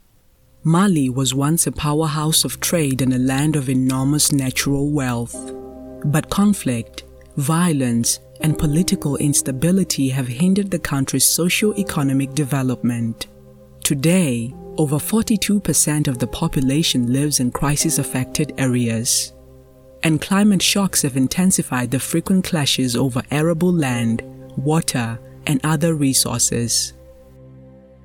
authentic, authoritative, bright, captivating, commercial, empathic, resonant, soothing
With a naturally warm and articulate tone, she delivers everything from soulful narration to vibrant commercial reads with clarity and purpose.
DocieVO.mp3